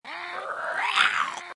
Groan Téléchargement d'Effet Sonore
Groan Bouton sonore
Animal Sounds Soundboard1 730 views